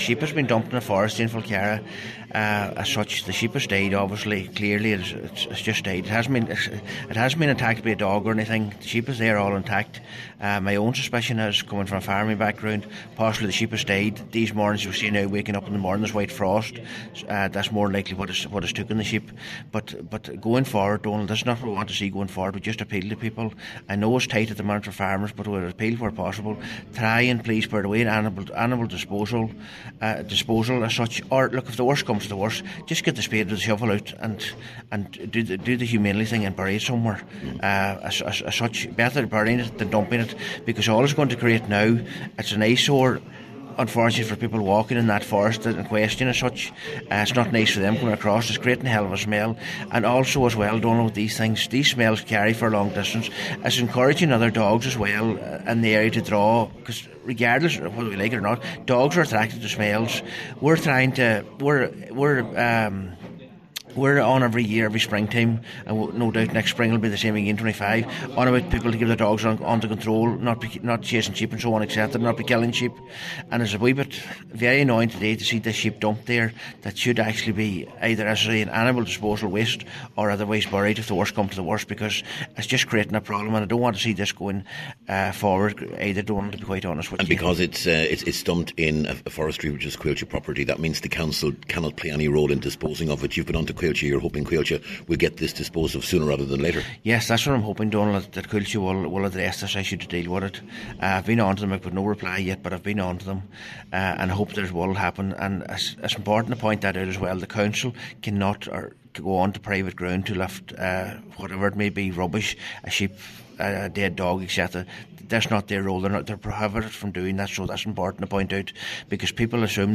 Cllr McClafferty hopes that a removal will be carried out shortly: